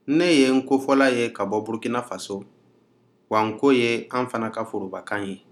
Dialogue: [nko]ߒߞߏ ߞߊ߲ ߝߐ[/nko]
This is a dialogue of people speaking Nko as their primary language.